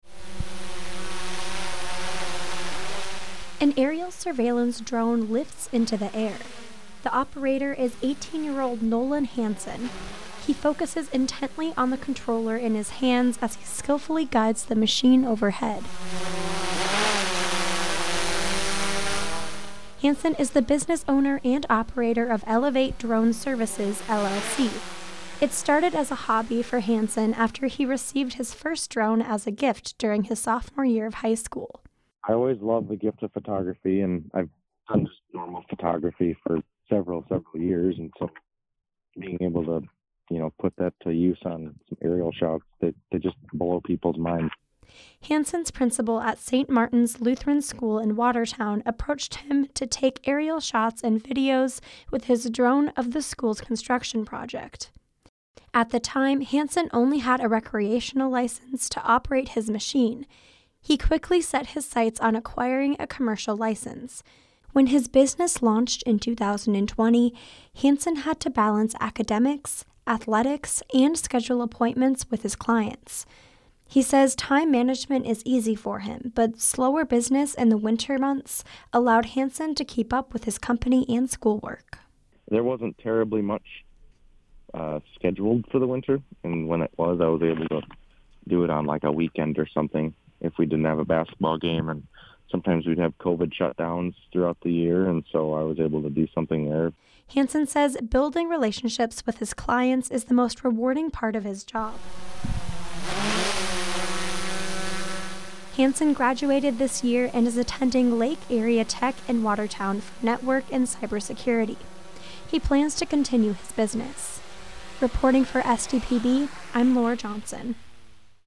An aerial surveillance drone lifts into the air, its four propellors buzz furiously as they launch the contraption.